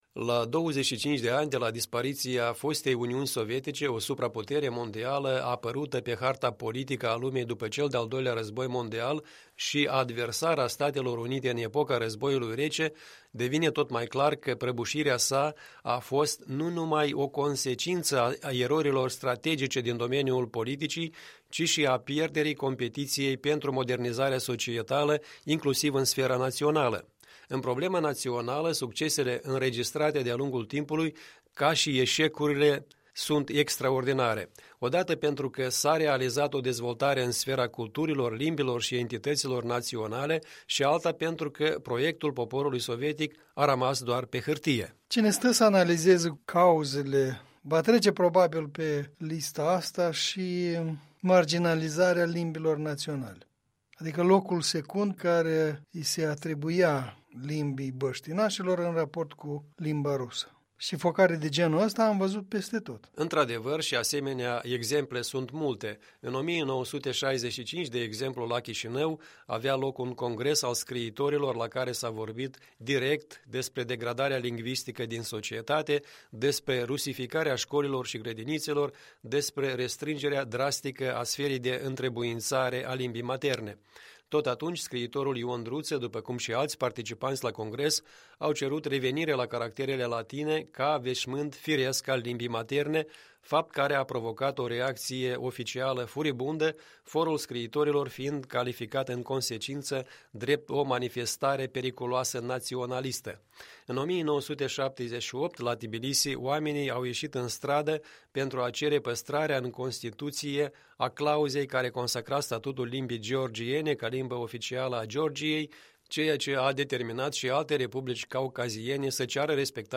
Istoricul și publicistul
în dialog